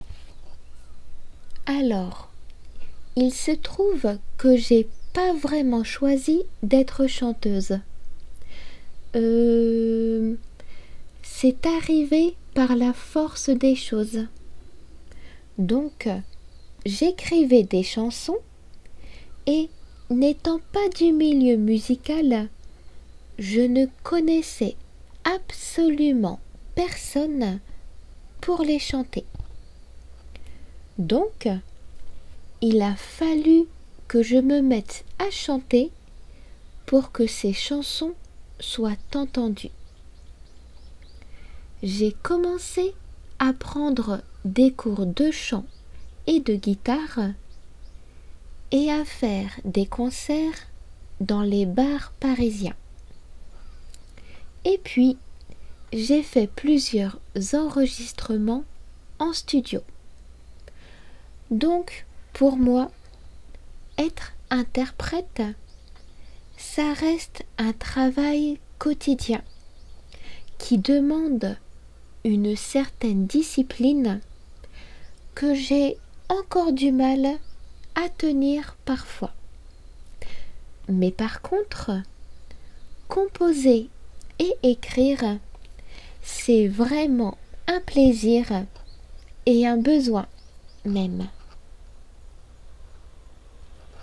音楽活動に携わっているある人の語りです。